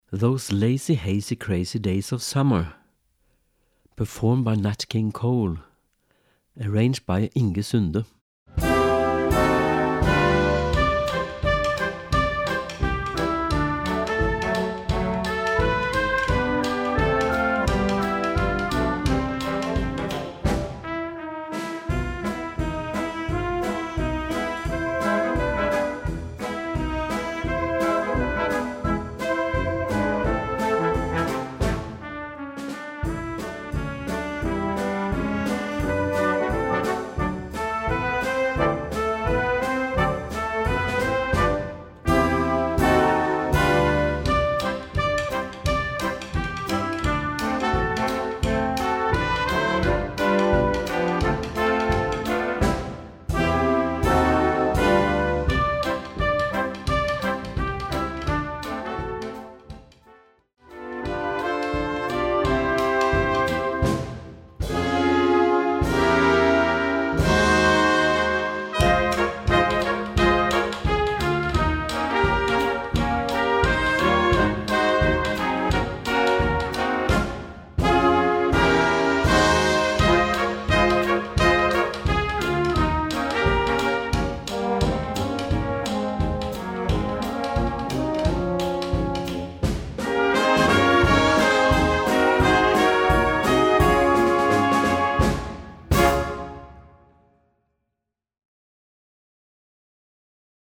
Gattung: für Jugendblasorchester
Besetzung: Blasorchester